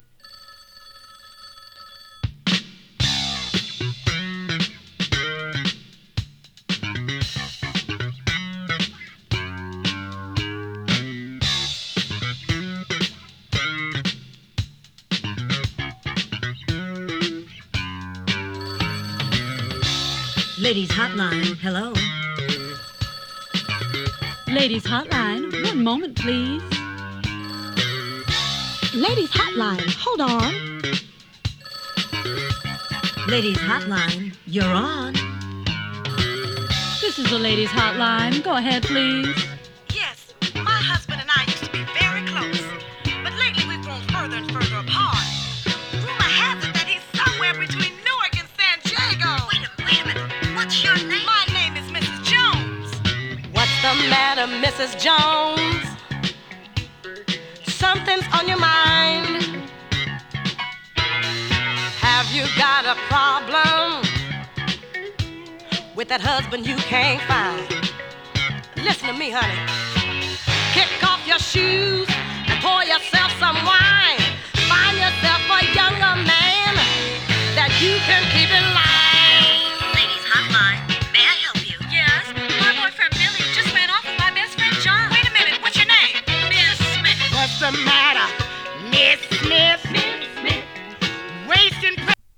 スウィングジャズボーカル